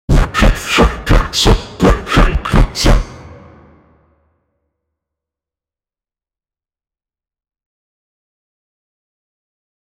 Cinematic and realistic. 0:15 in boss fight, boss goes anger so sounds like hollow knight sound 0:10 Create a sound for a sci-fi turret firing in a video game.
in-boss-fight-boss-goes-jraf7vri.wav